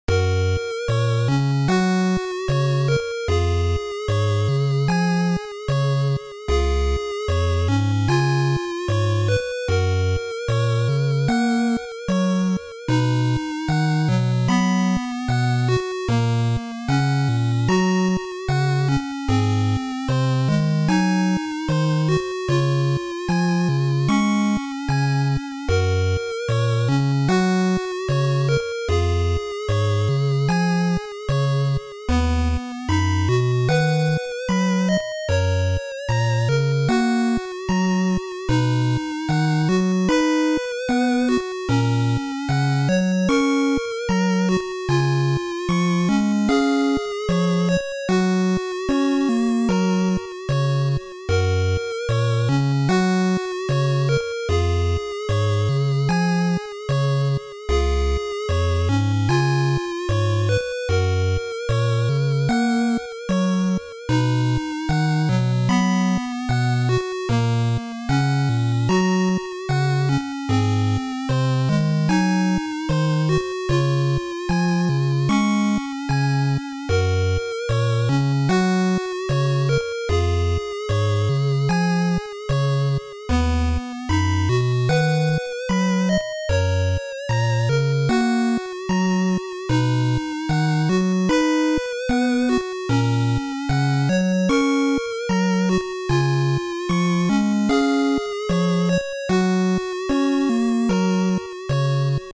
OST 温馨的旋律、柔和的8bit风
在这样唯美的音乐之中，太阳缓缓地升起，照耀在阴沉的城市废墟之上。